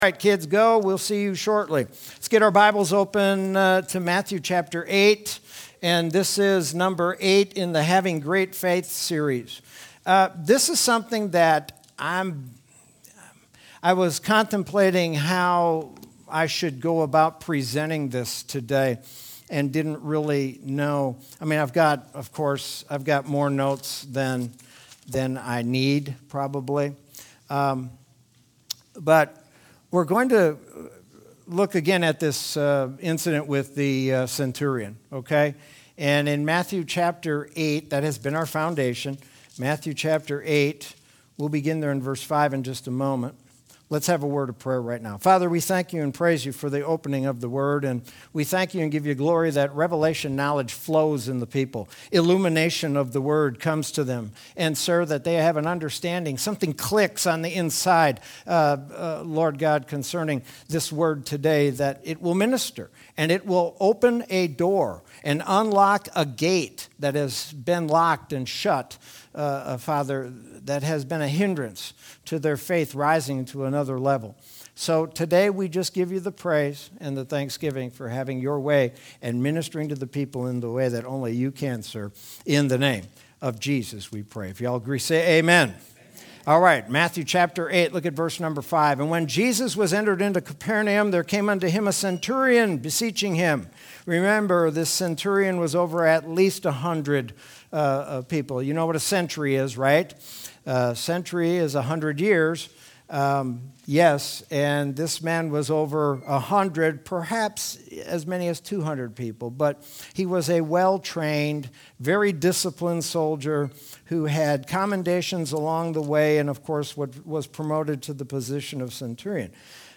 Sermon from Sunday, August 1st, 2021.